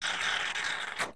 plunger.ogg